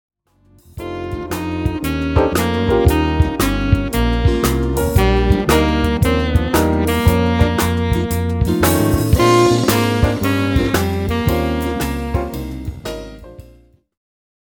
groep6_les1-4-2_jazzmuziek2.mp3